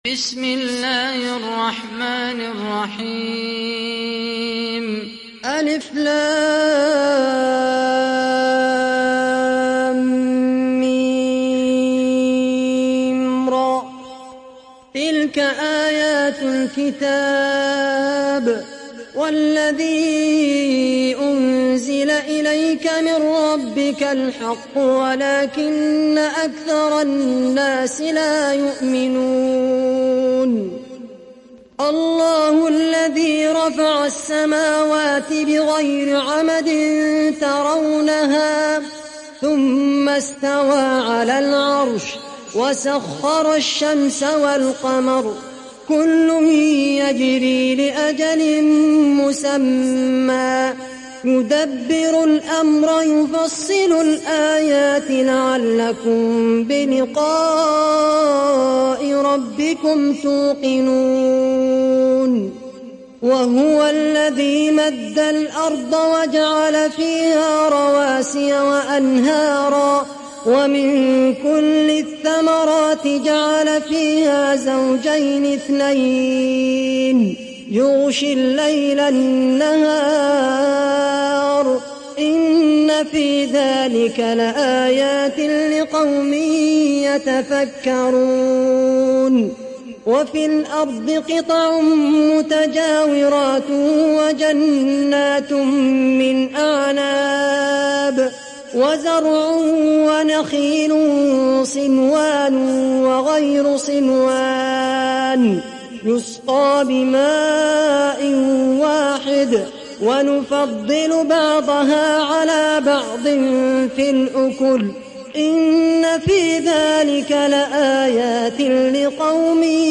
دانلود سوره الرعد mp3 خالد القحطاني روایت حفص از عاصم, قرآن را دانلود کنید و گوش کن mp3 ، لینک مستقیم کامل